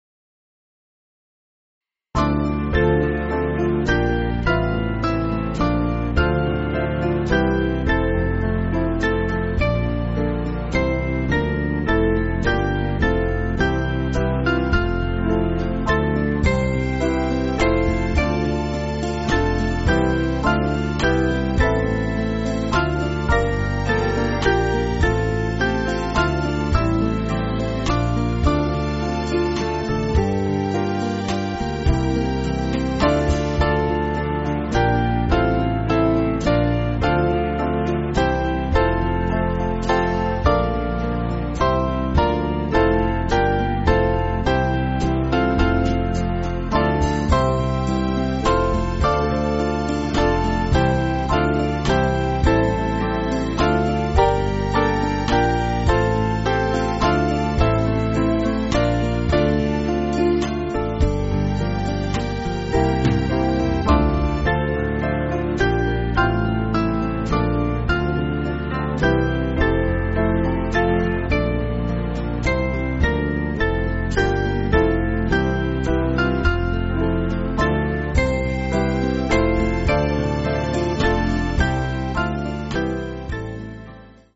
Small Band
(CM)   5/Dm